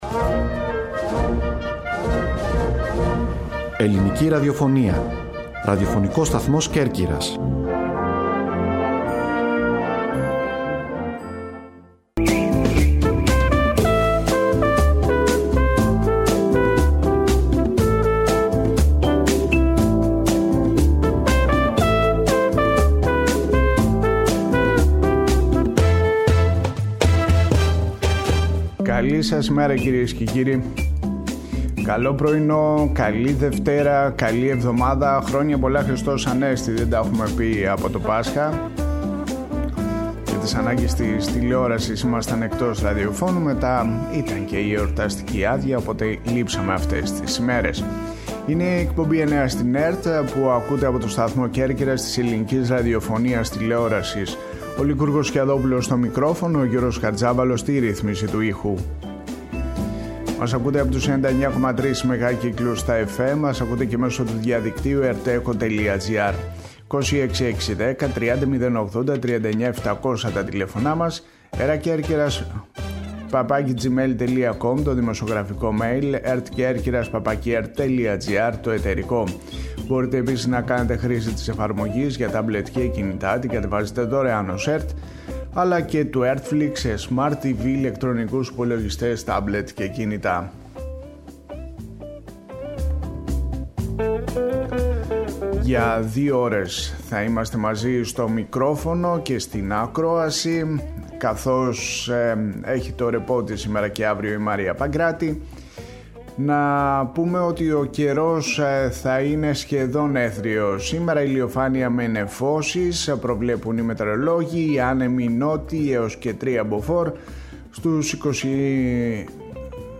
«Εννέα στην ΕΡΤ» Οριοθέτηση της ειδησιογραφίας στην Κέρκυρα, την Ελλάδα και τον κόσμο, με συνεντεύξεις, ανταποκρίσεις και ρεπορτάζ.